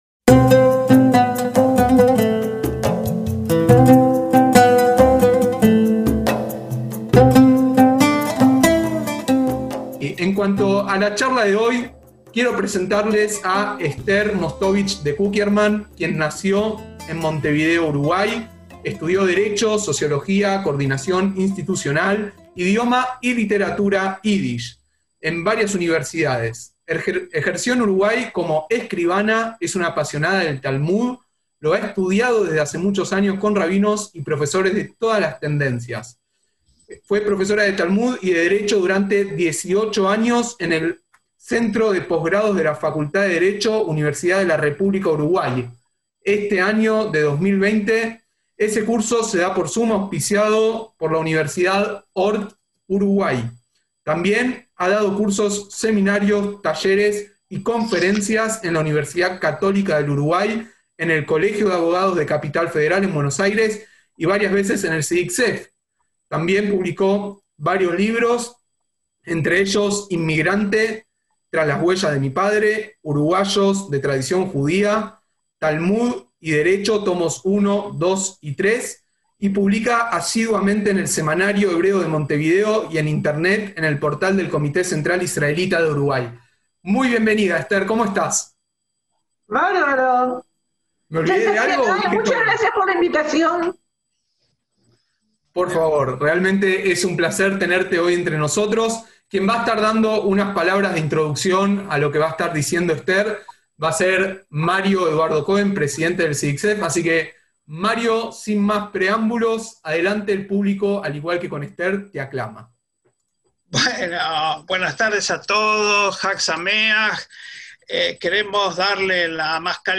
ACTOS "EN DIRECTO" -